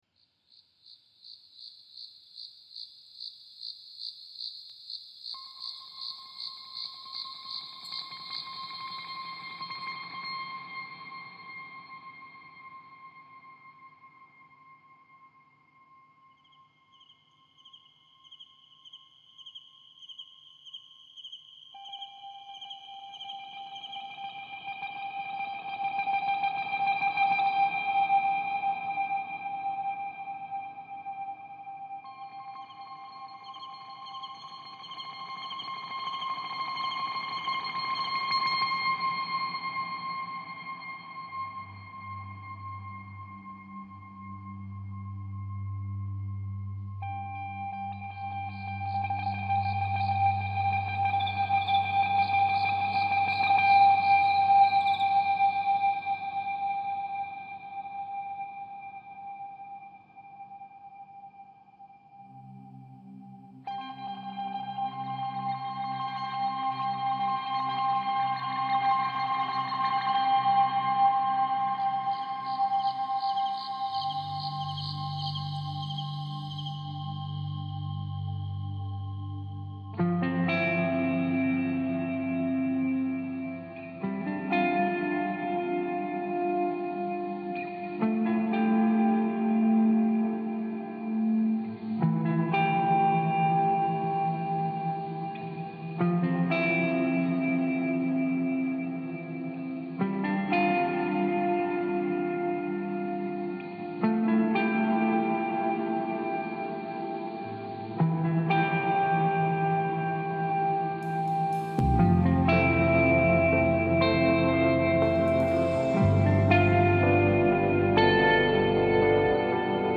這星期我改了點mixing，弄了個新version。
不知你有沒有聽過後搖滾（Post-Rock)，我想用後搖來反映那心情。